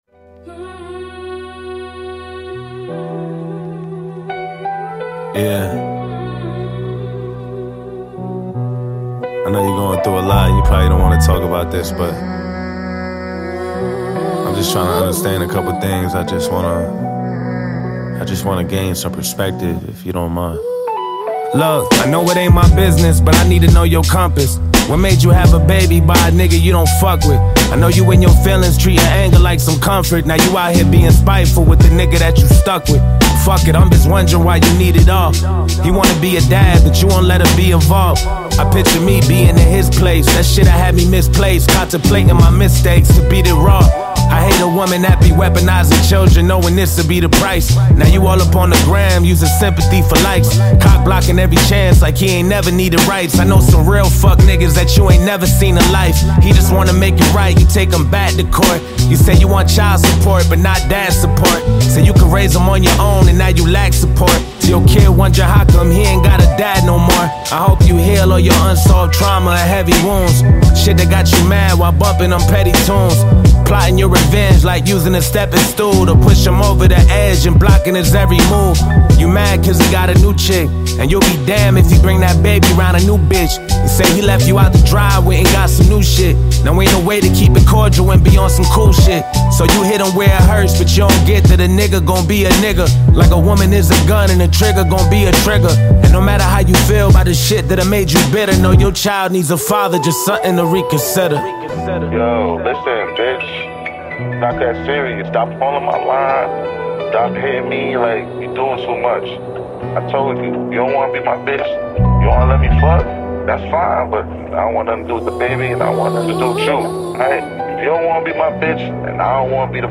It delivers a sound that feels both fresh and consistent.
clean production